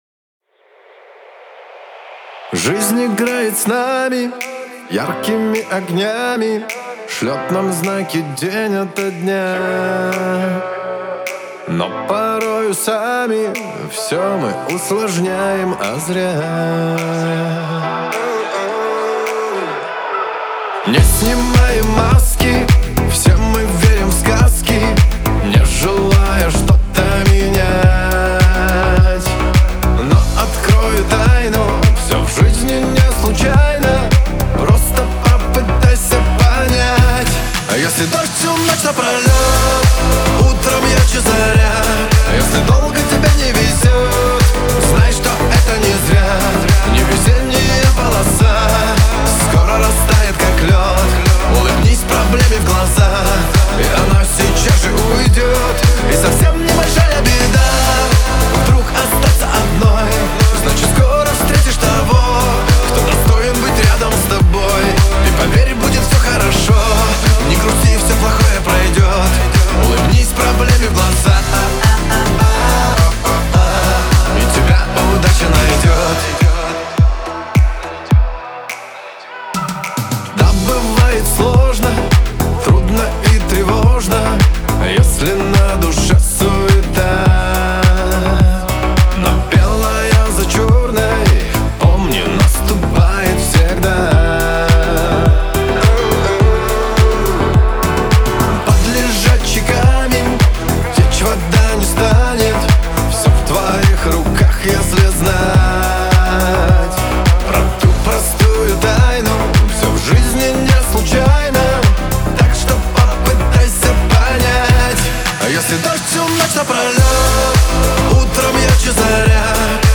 Танцевальная музыка
песни для танцев
веселые песни